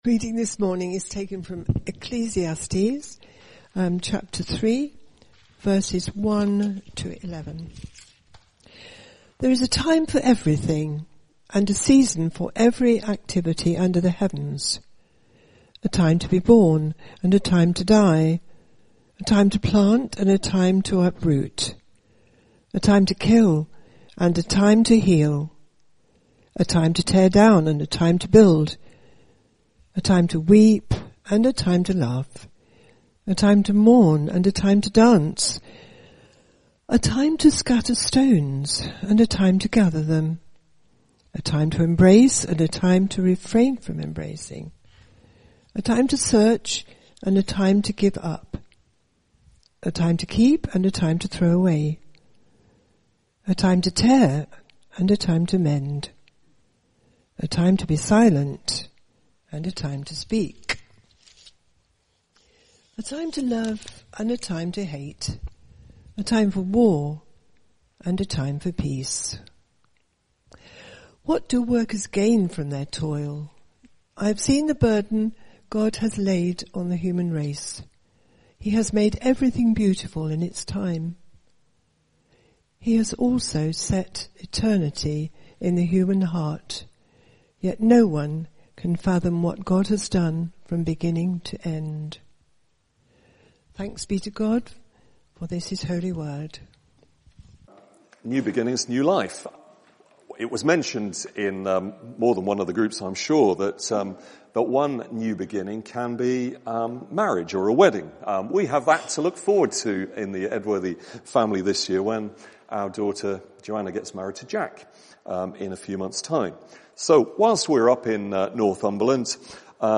Sermon (Audio) - Well Street United Church